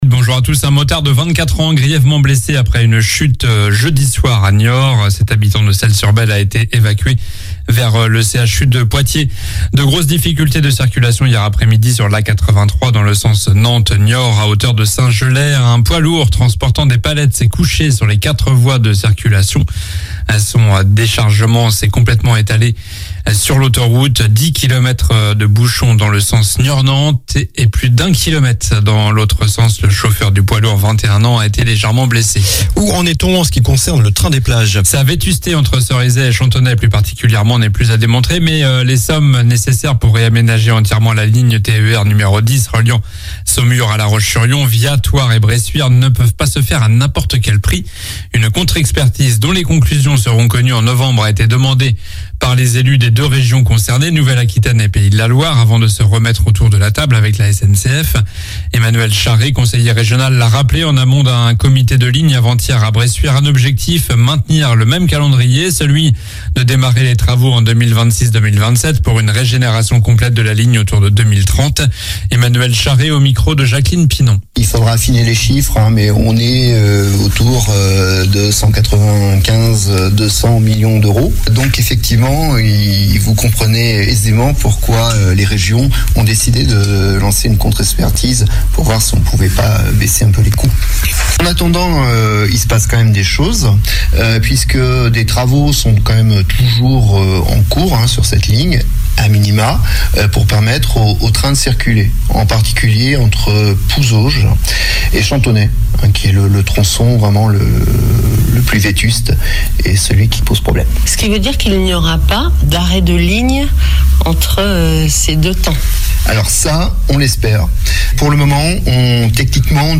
Journal du samedi 10 juin (matin)